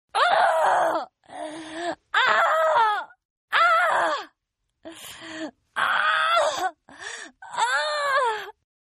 Звук напряжения в родах